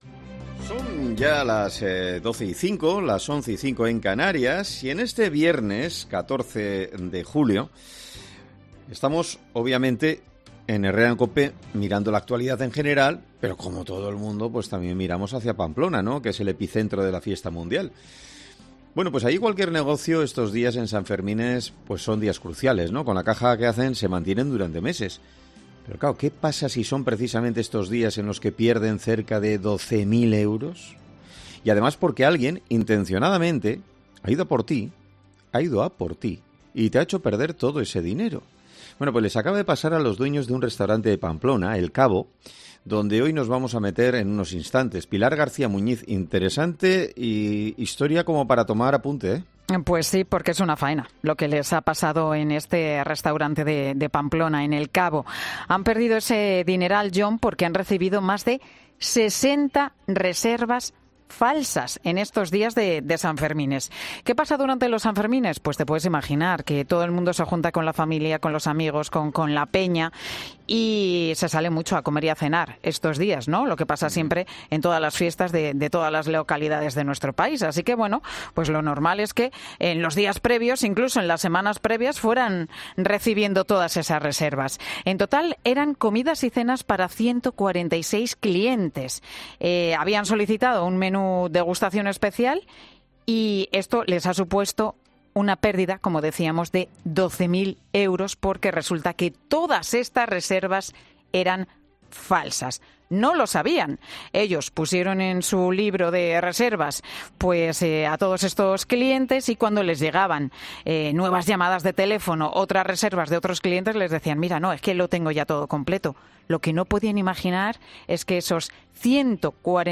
AUDIO: En 'Herrera en COPE' hablamos con uno de los dueños del restaurante afectado por esta estafa